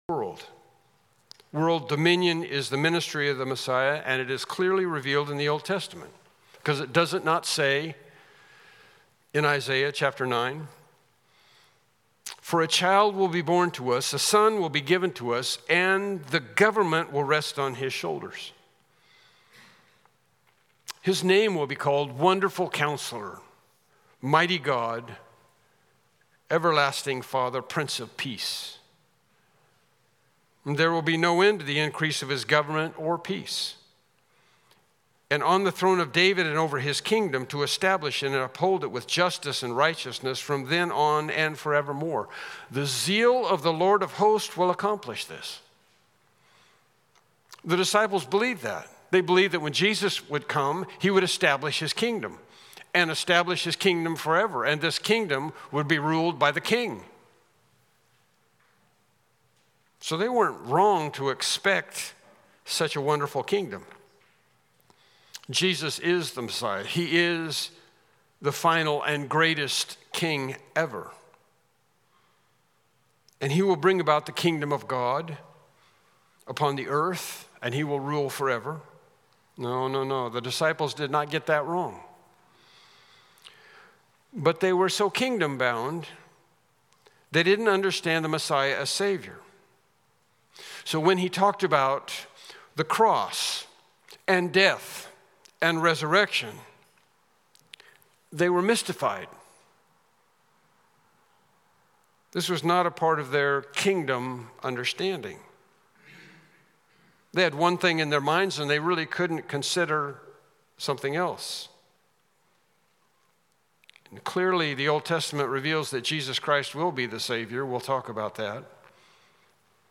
Unknown Service Type: Morning Worship Service « Lesson 18